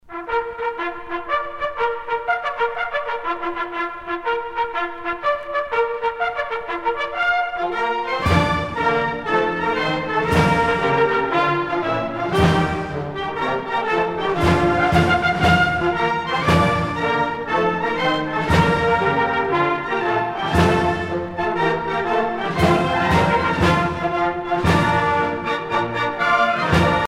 à marcher
militaire
Pièce musicale éditée